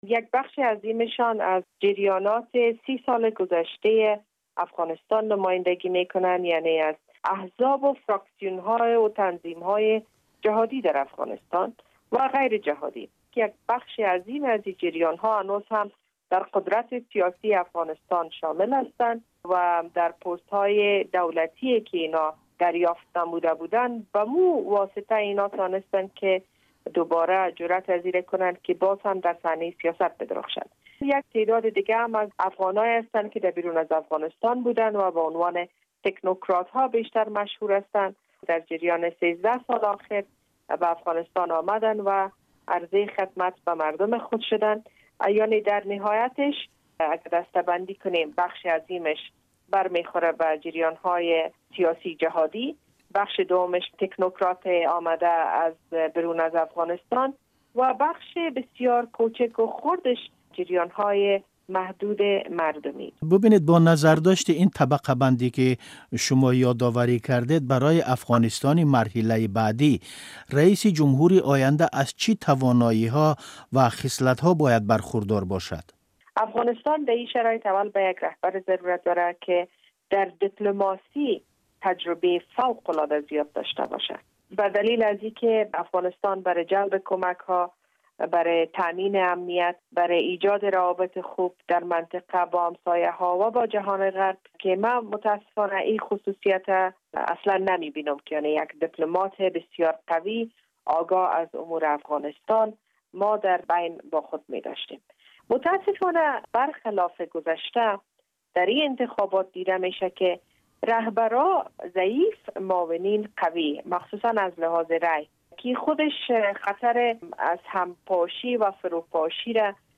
Гуфтугӯ бо Шукрияи Борикзай